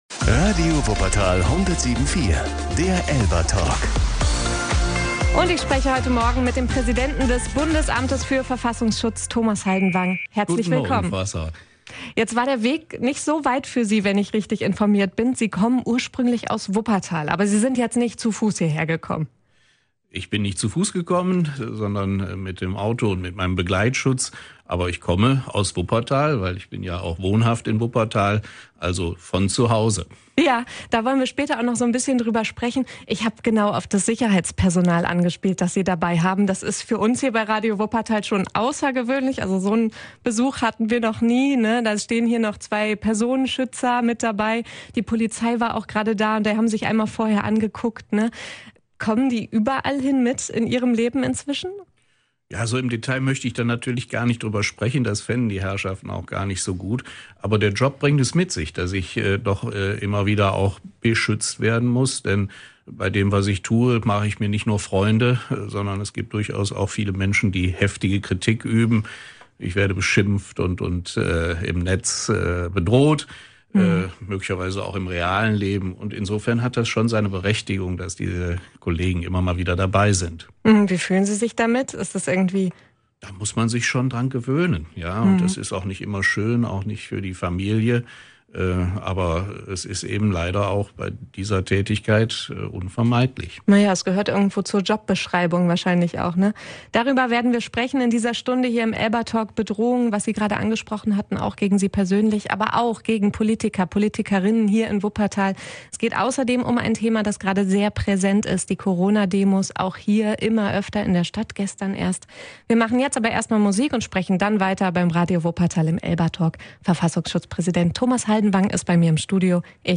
ELBA-Talk mit Thomas Haldenwang